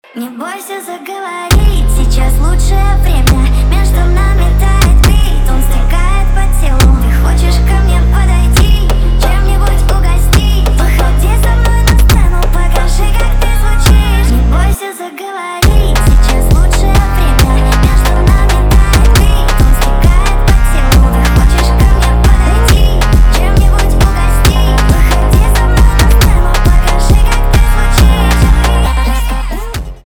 поп
битовые , басы , качающие